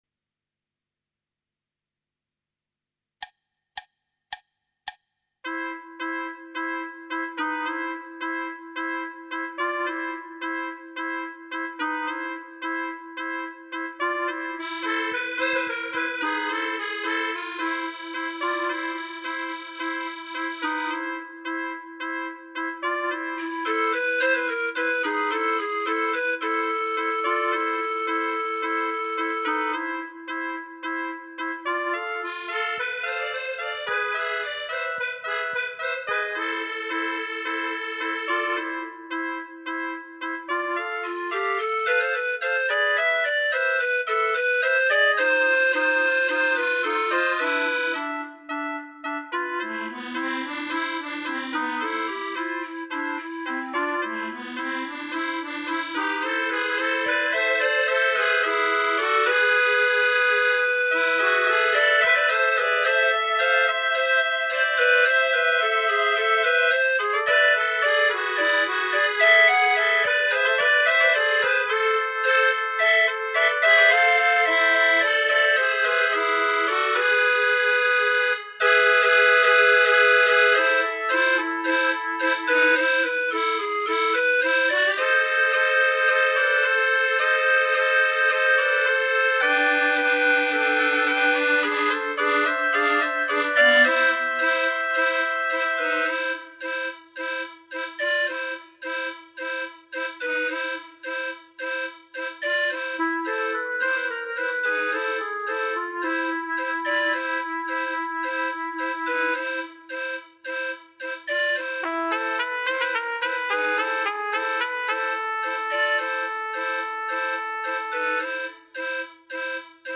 Right click to download Tango minus Bass